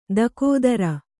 ♪ dakōdara